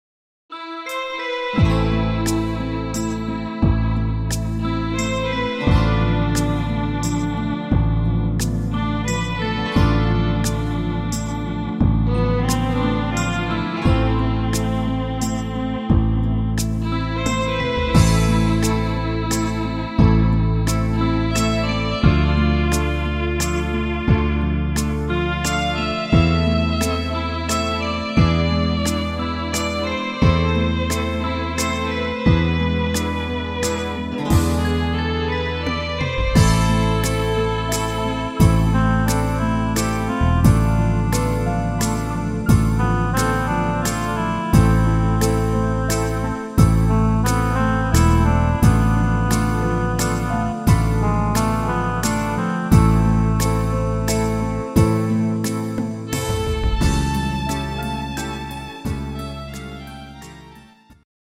langsamer Walzer